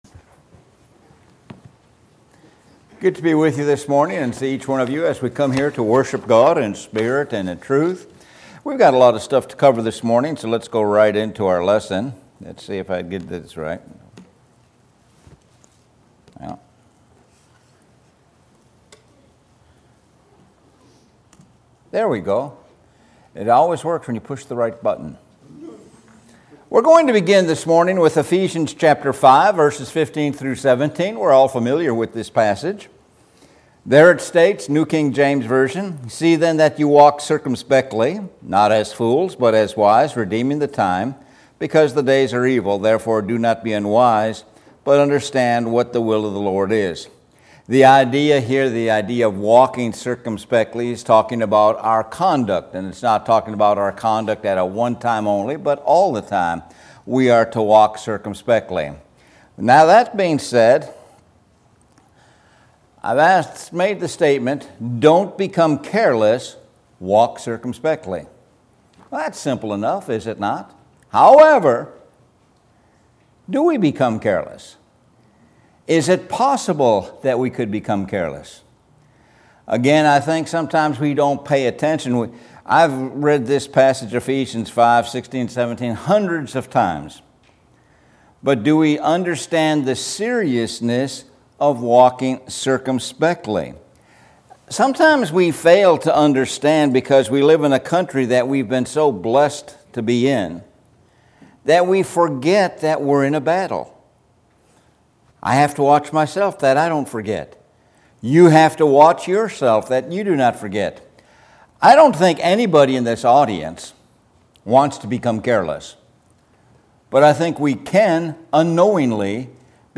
Sun AM Worship -Sermon